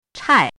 chinese-voice - 汉字语音库
chai4.mp3